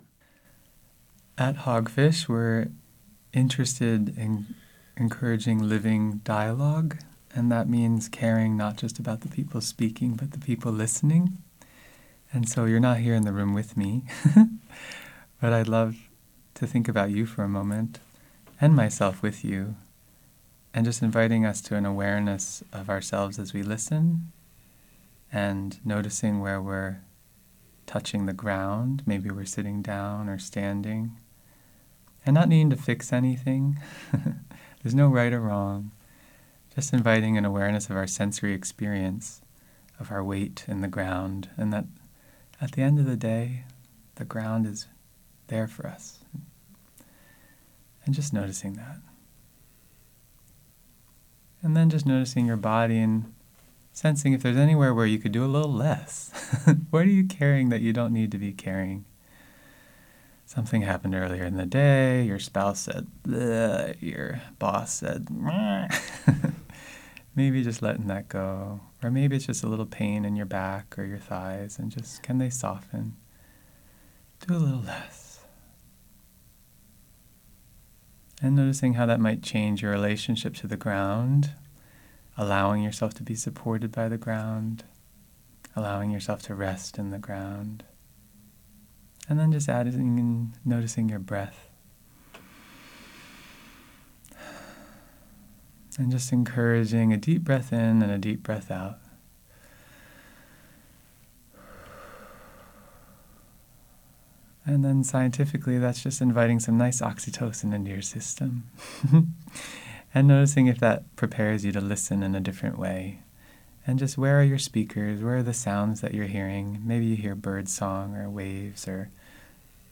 sota-hogfish-mindfulness-exercise.mp3